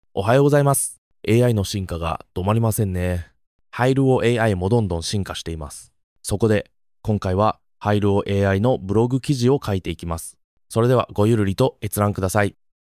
音声生成機能[Speech Synthesis]
性別や年齢層などから、好みの音声を選択します。
流暢な日本語で出力されます。